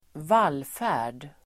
Ladda ner uttalet
Uttal: [²v'al:fä:r_d]